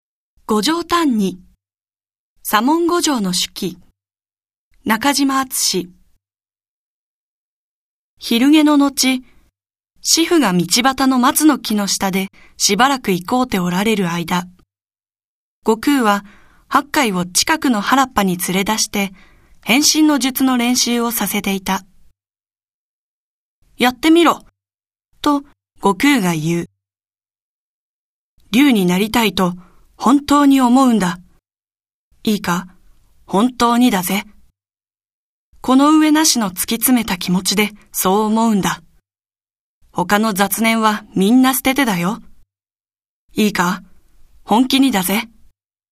• 弊社録音スタジオ
朗読ＣＤ　朗読街道112「悟浄歎異・牛人」中島敦
朗読街道は作品の価値を損なうことなくノーカットで朗読しています。